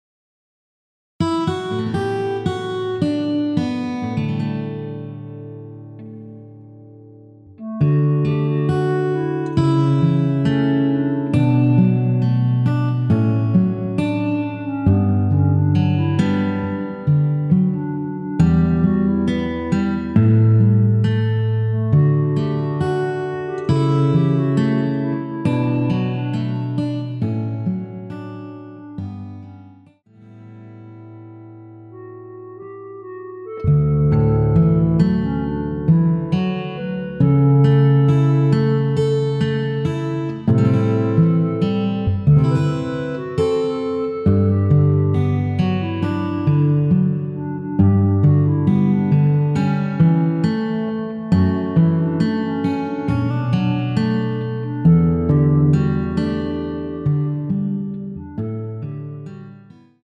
원키에서(-2)내린 멜로디 포함된 MR입니다.
D
앞부분30초, 뒷부분30초씩 편집해서 올려 드리고 있습니다.